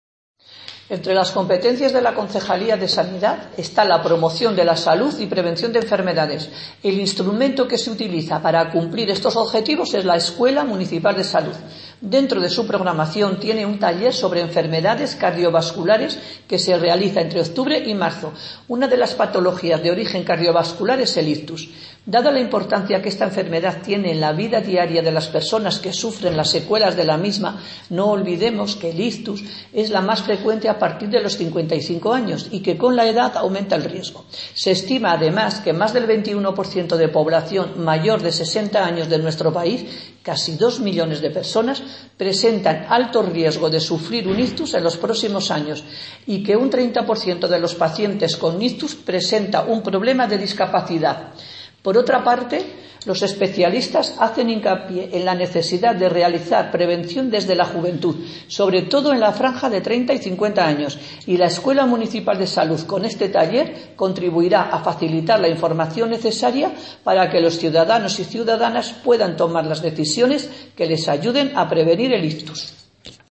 Audio - Ana María Rodrigo (Concejala Concejalía de Igualdad, Sanidad y Mayores)